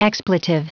Prononciation du mot expletive en anglais (fichier audio)
Prononciation du mot : expletive